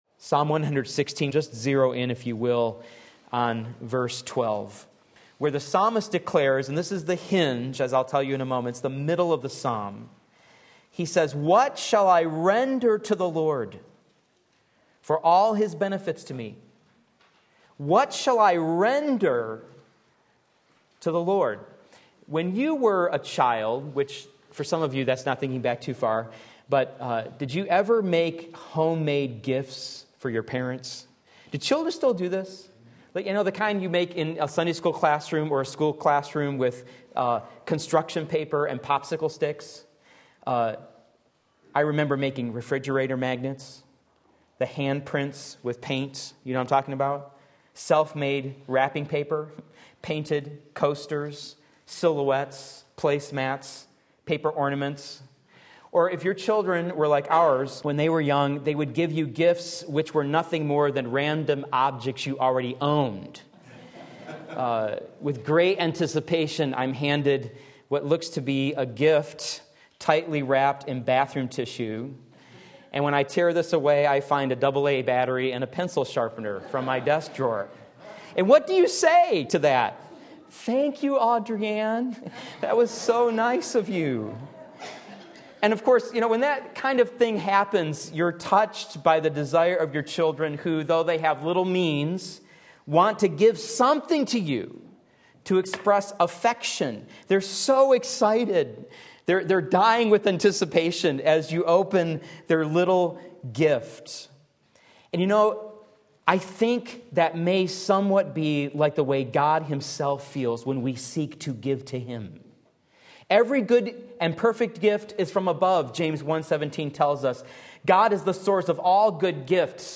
Sermon Link
Thankfulness Worthy of God Psalm 116 Sunday Morning Service